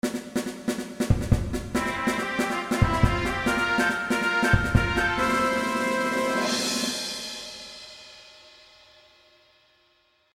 And both seem a tad quiet.